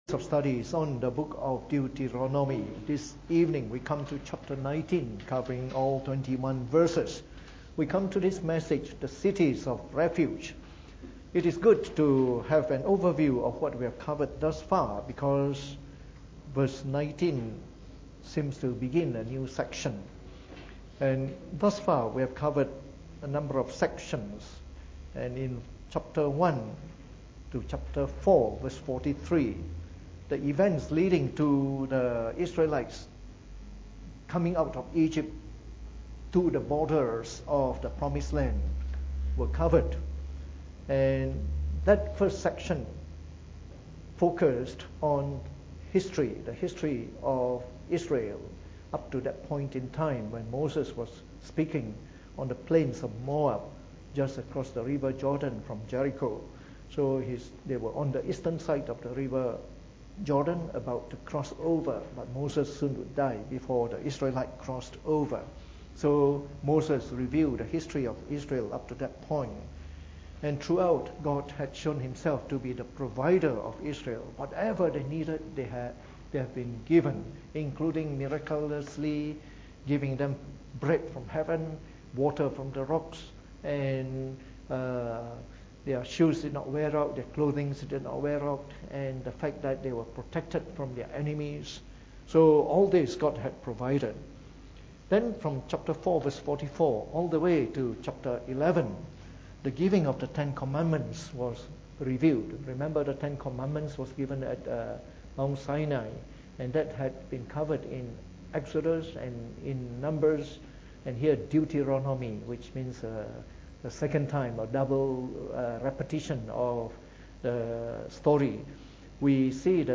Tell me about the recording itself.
Preached on the 20th of June 2018 during the Bible Study, from our series on the book of Deuteronomy.